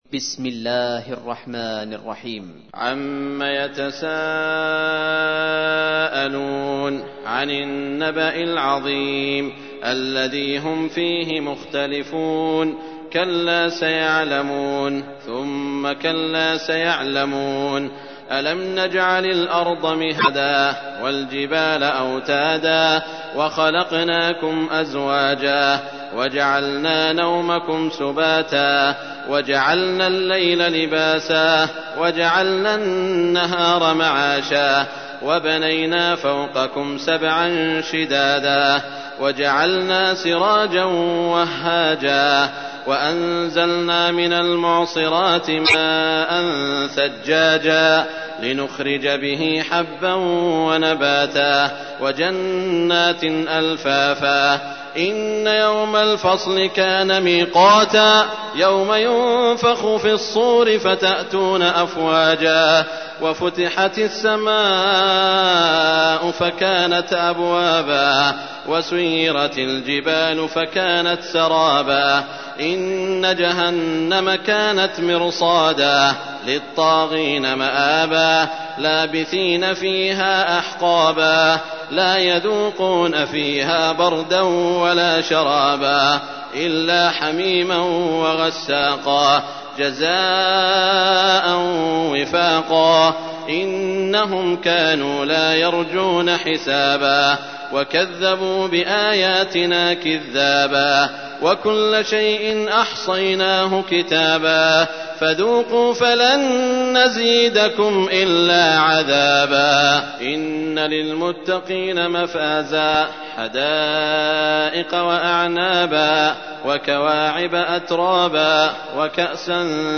تحميل : 78. سورة النبأ / القارئ سعود الشريم / القرآن الكريم / موقع يا حسين